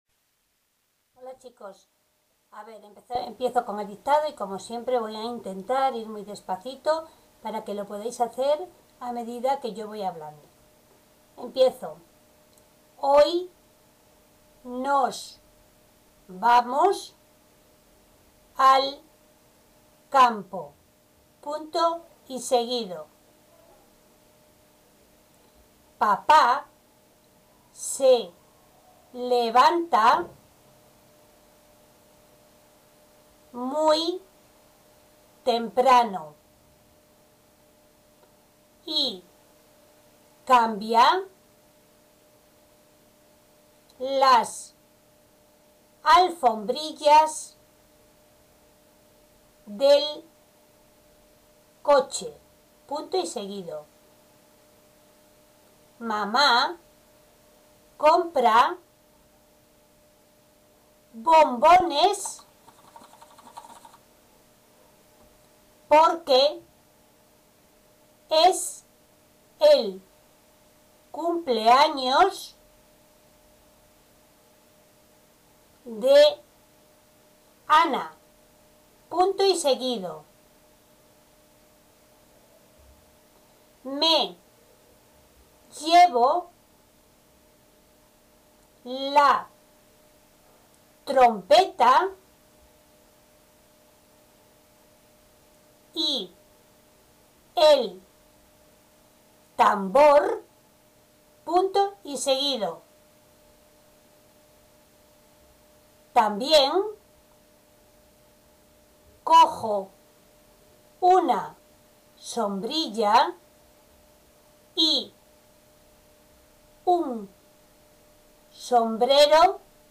Dictado
Dictado-27_de_Mayo.mp3